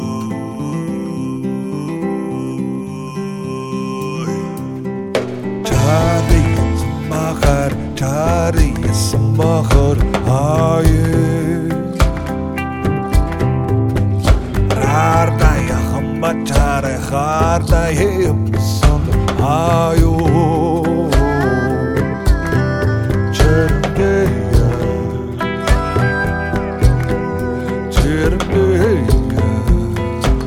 Musique du Monde